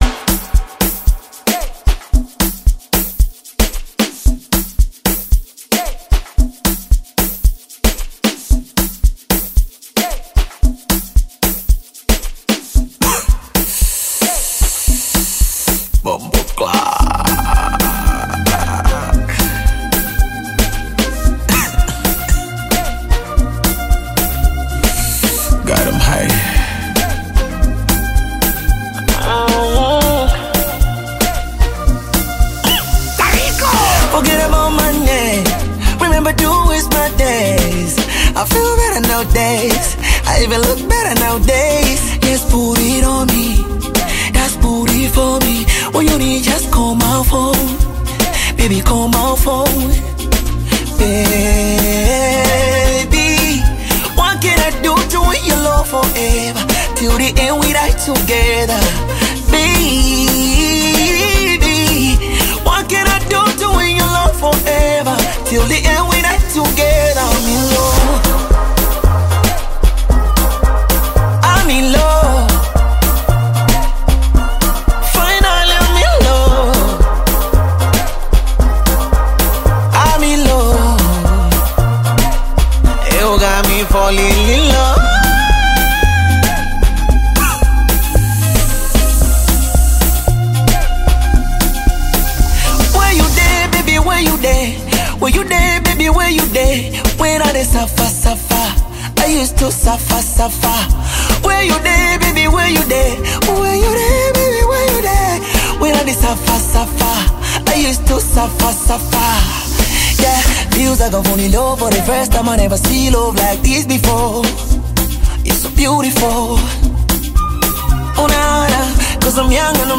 Afro-Beat/Bongo-Flava single
passionate violin
Genre: Amapiano